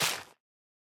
tilt_down2.ogg